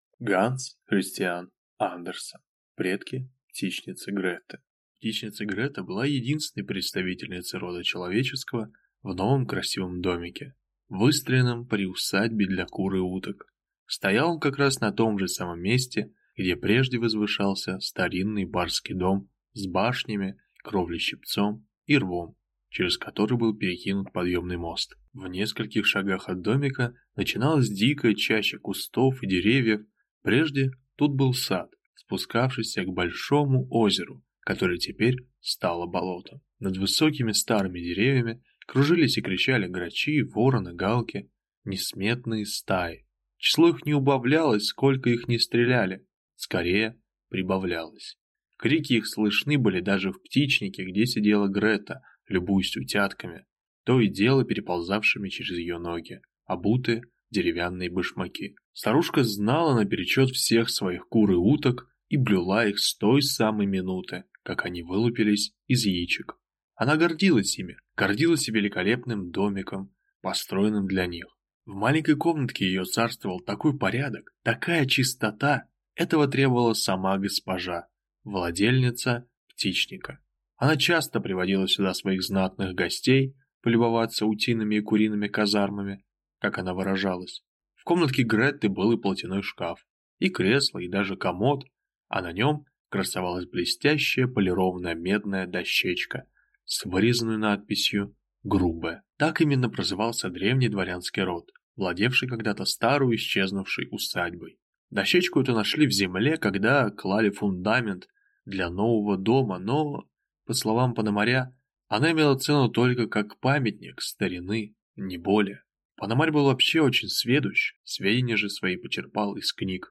Аудиокнига Предки птичницы Греты | Библиотека аудиокниг